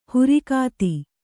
♪ hurikāti